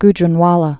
(gjrən-wälə, gj-)